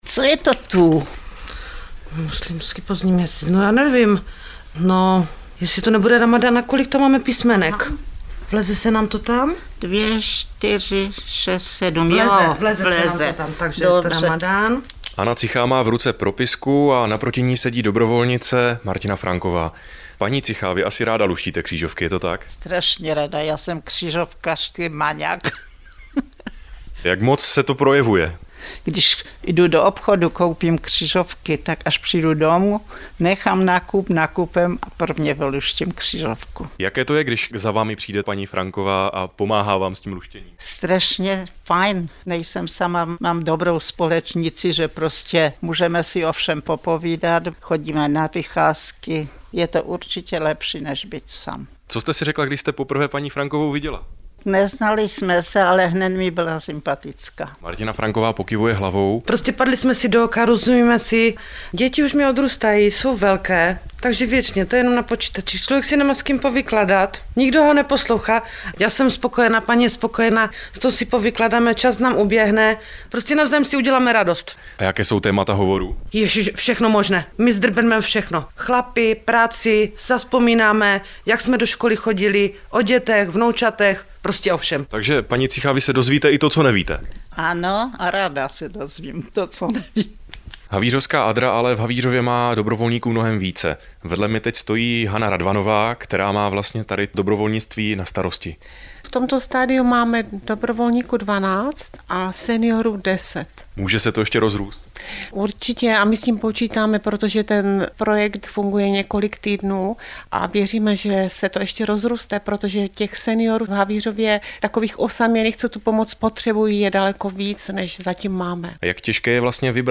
Dobrovolnictví domů - Reportáž na vlnách Českého rozhlasu Ostrava